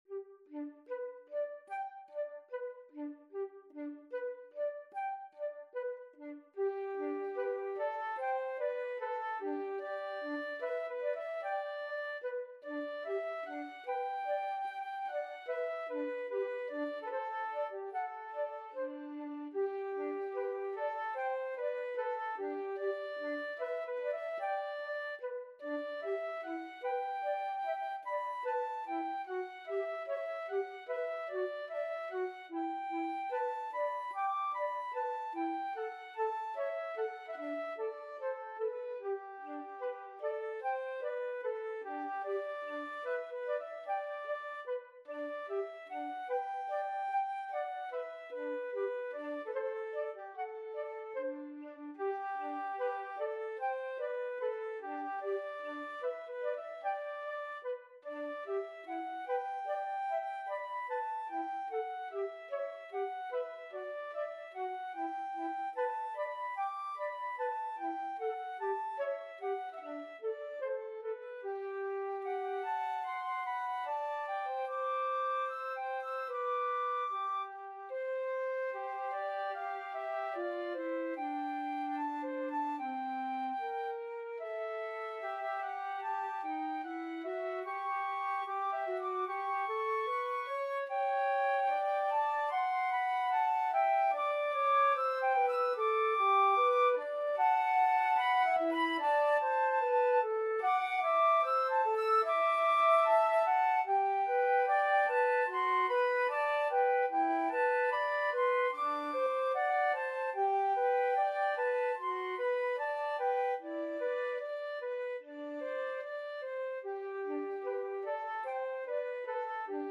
Free Sheet music for Flute Duet
4/4 (View more 4/4 Music)
G major (Sounding Pitch) (View more G major Music for Flute Duet )
Andantino quasi allegretto ( = 74) (View more music marked Andantino)
Flute Duet  (View more Intermediate Flute Duet Music)
Classical (View more Classical Flute Duet Music)